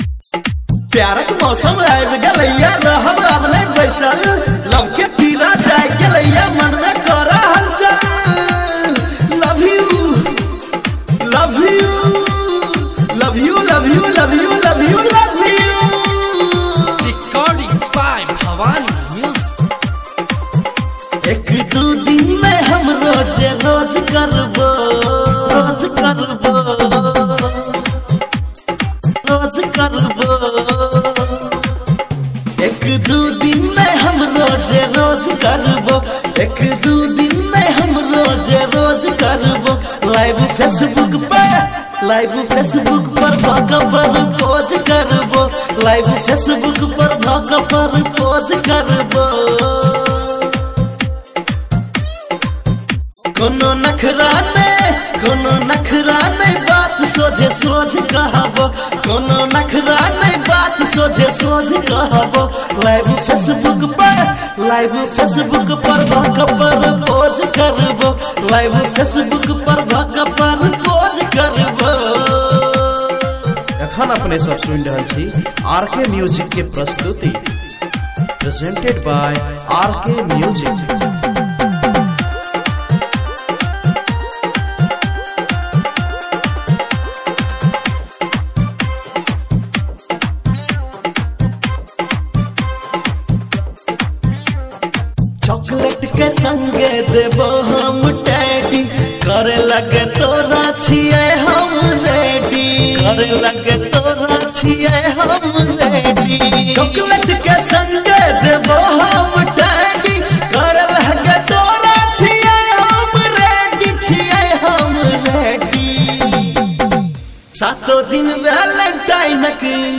Maithili Song